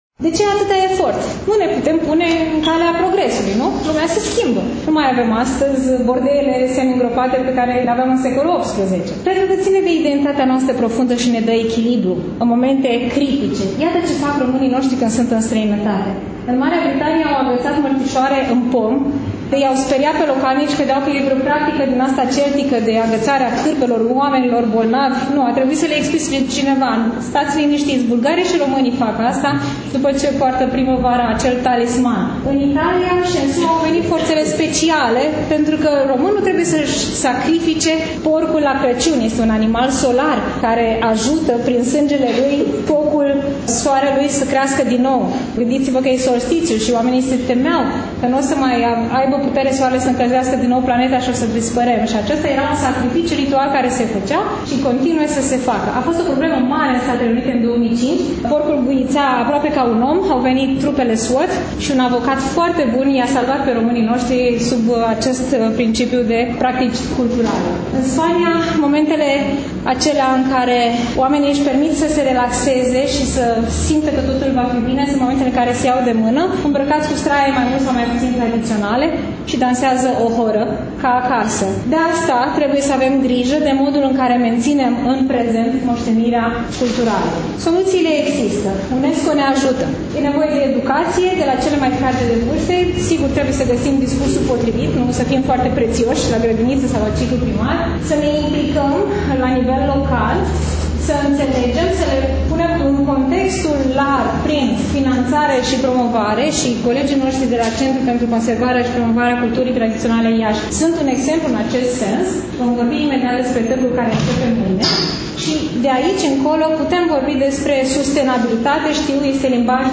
Dragi ascultători, astăzi, după cum bine știți, relatăm de la expoziţia de carte „Satul românesc”, manifestare culturală desfășurată, nu demult, la Iași, în incinta Bibliotecii Centrale Universitare „Mihai Eminescu”.